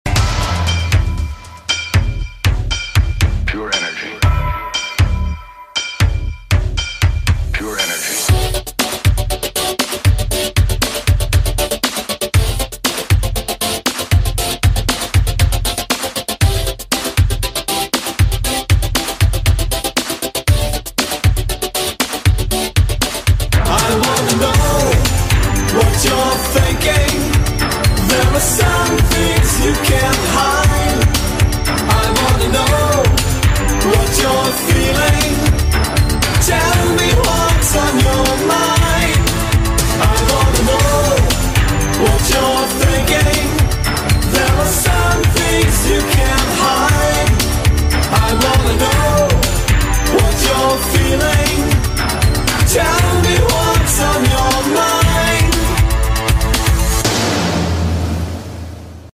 Jamming to the music on the radio 📻 AI-GENERATED style